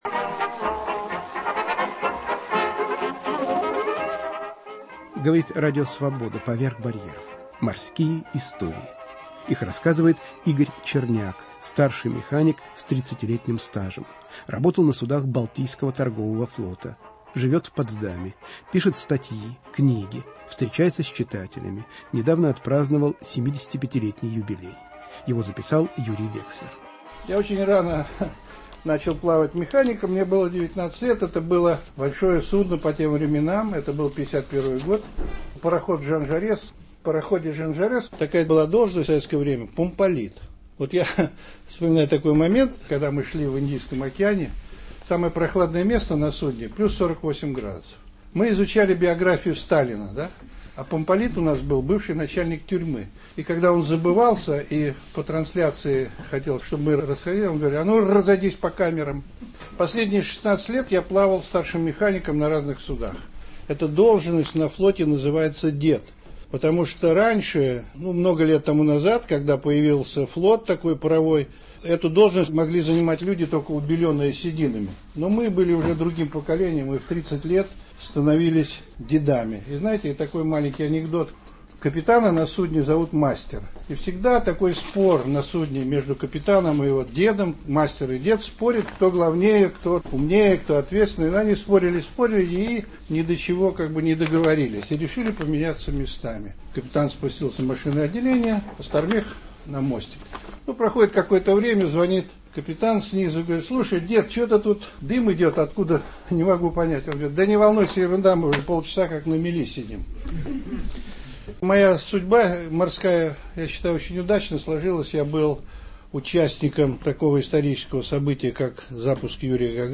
"Морские истории". Ветеран советского флота рассказывает о службе на море, о противостоянии СССР и США во время кризиса в Карибском море, о роли флота в подготовке полёта Юрия Гагарина в космос.